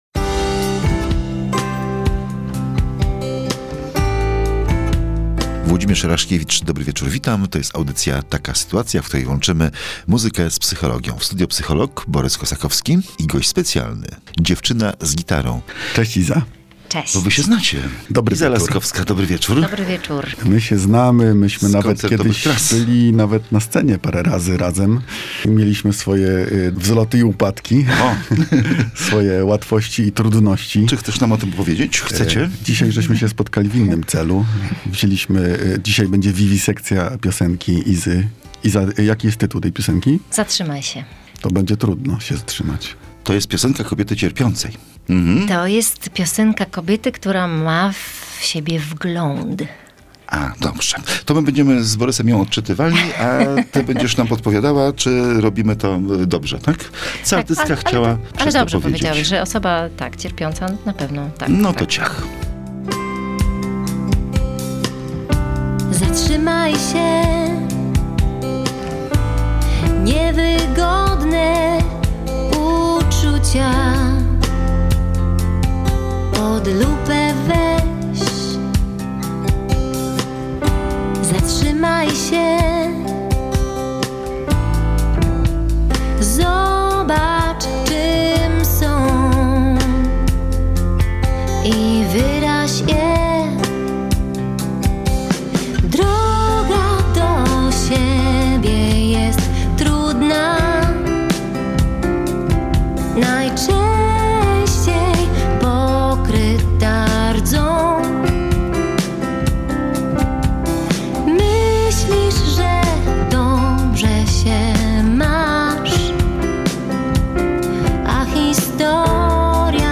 „Zatrzymaj się”. Rozmowa